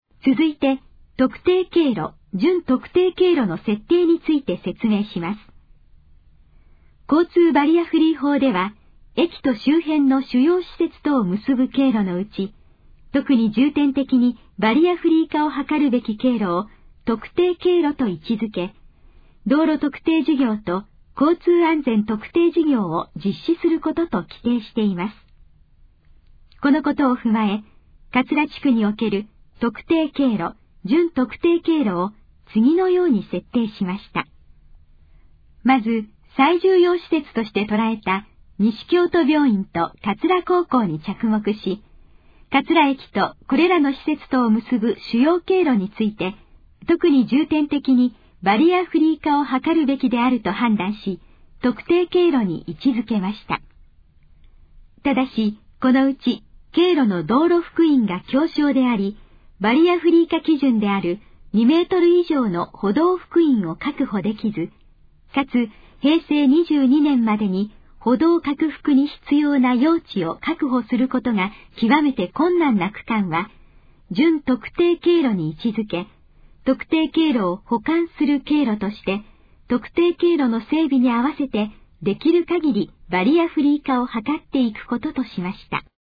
以下の項目の要約を音声で読み上げます。
ナレーション再生 約194KB